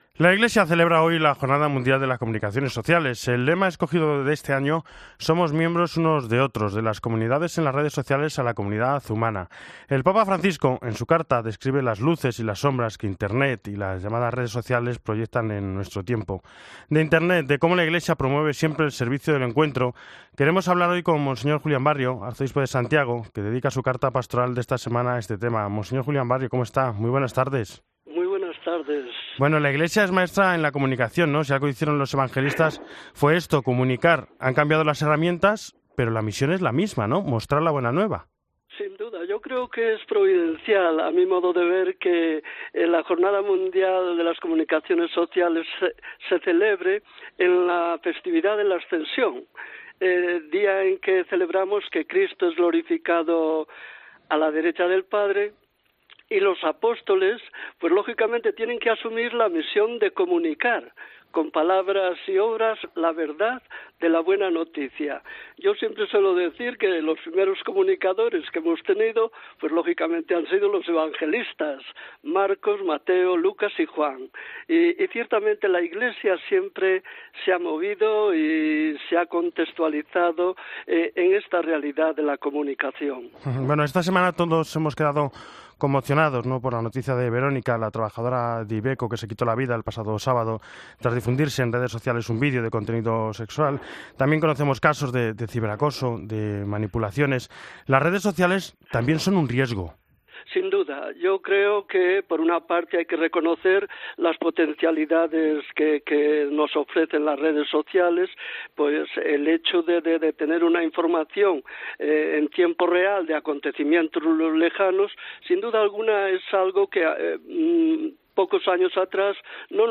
Hoy en El Espejo hemos entrevistado a Monseñor Juilán Barrio arzobispo de Santiago de Compostela con ocasión de la Carta Pastoral que ha escrito para este Domingo de la Ascensión del Señor, Jornada Mundial de las Comunicaciones Sociales.